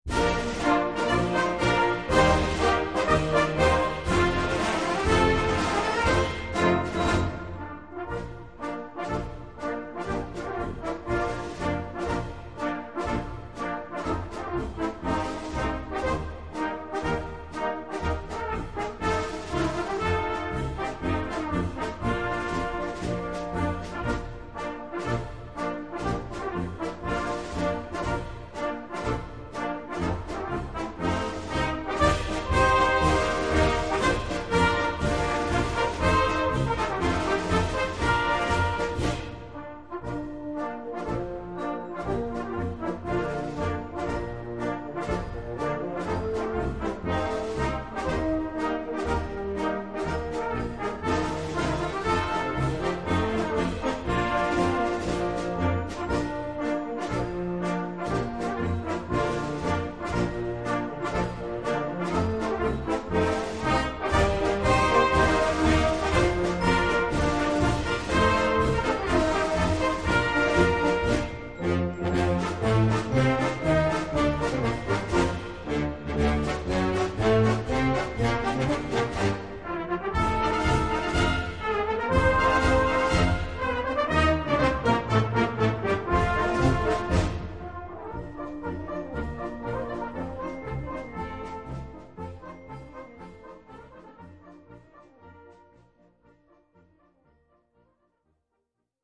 Gattung: Marsch
Besetzung: Blasorchester